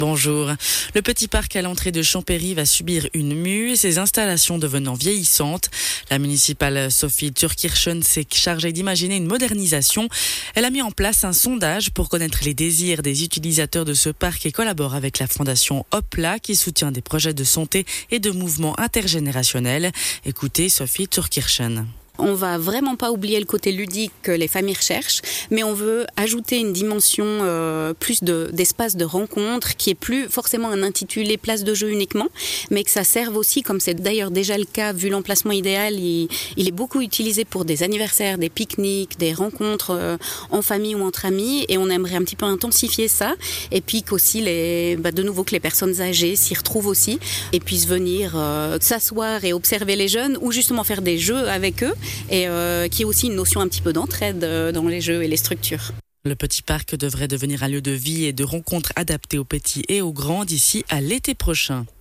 Parallèlement, le projet a été souligné dans un reportage radiophonique et la population a été interrogée sur ses besoins pour un tel parc.